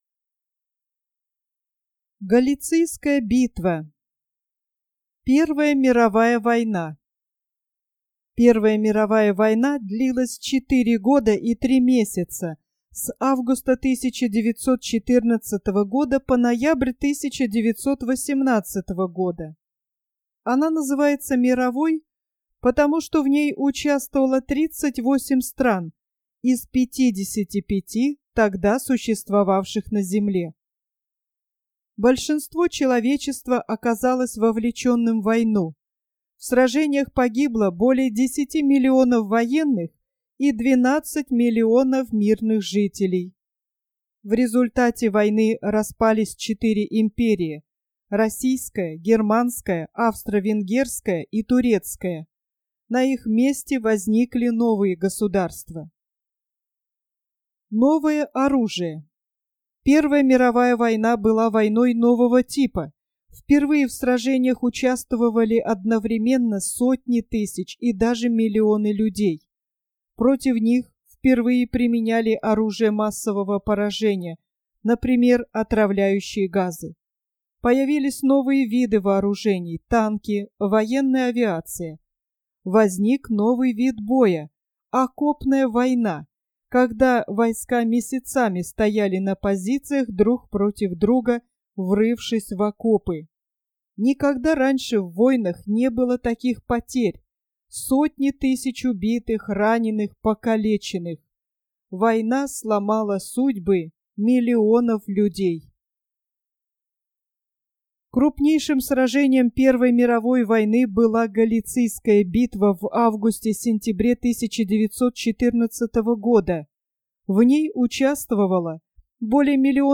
Познавательная аудиокнига для младших школьников "Победы русской армии и флота" из серии "Моя Россия", статья "Галицийская битва". Первая мировая война длилась 4 года и 3 месяца: с августа 1914 по ноябрь 1918.